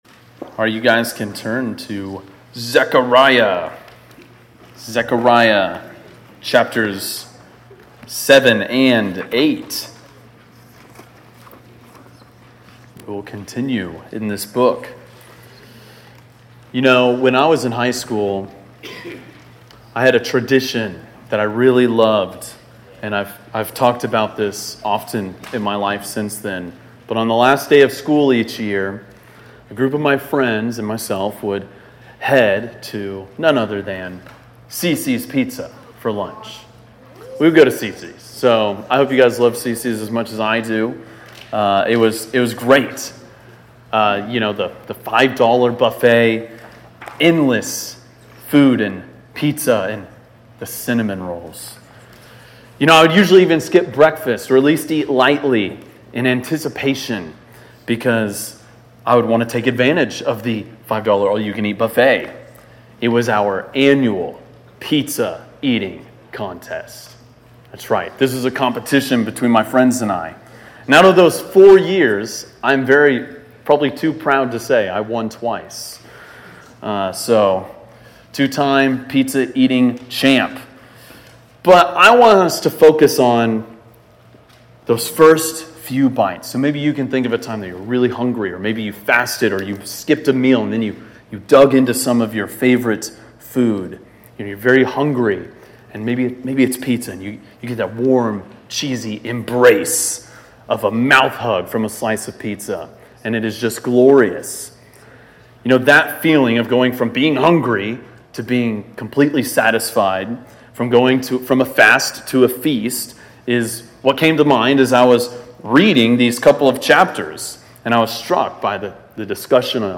preaches through Zechariah 7-8.